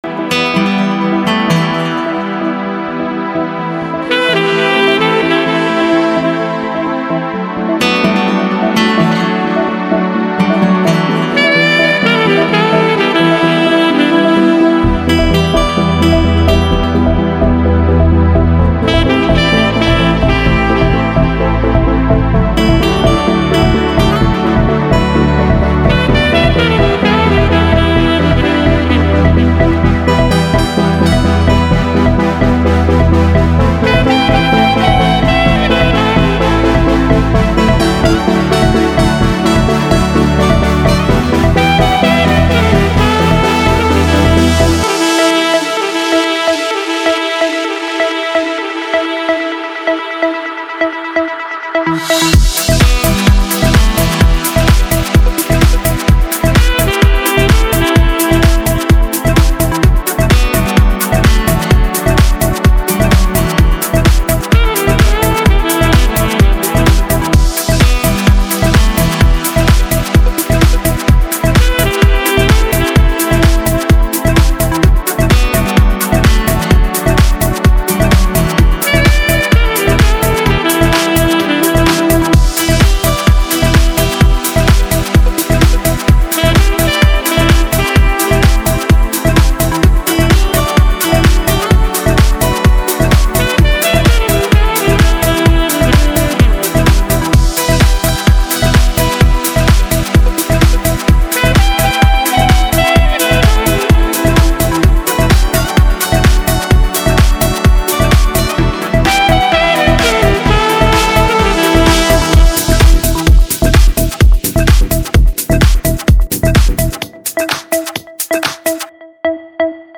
• Качество: 320, Stereo
гитара
без слов
инструментальные
Саксофон
Красивая музыка с саксом